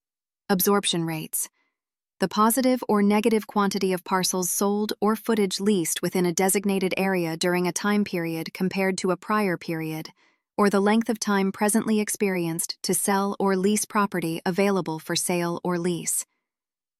Listen to the terms you’ll need to remember most with an audio reading of definitions while you think through them.